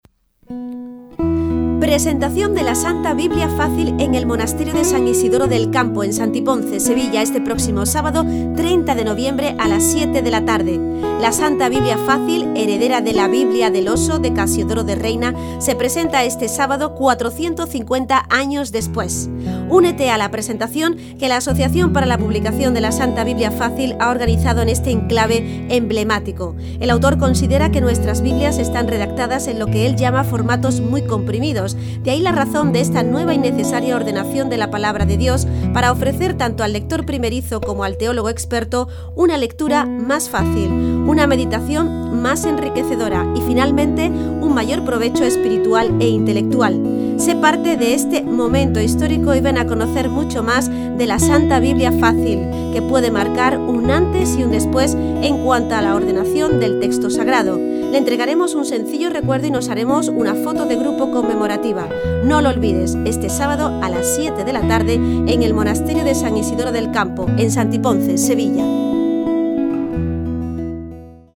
Cuña presentación LSBF